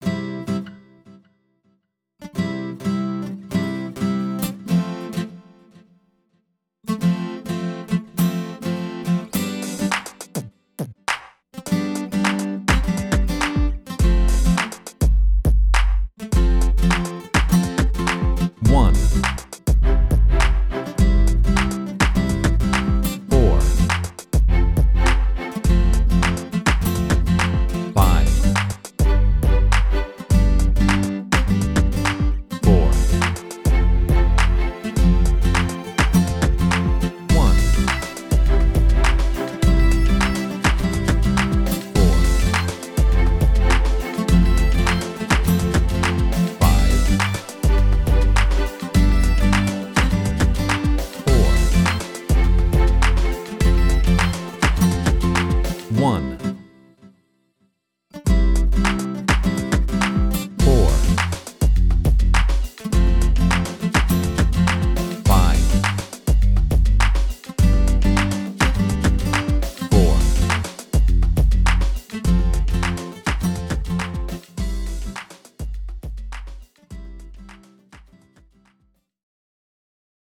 Pop
Training 2. I-IV-V-IV (C)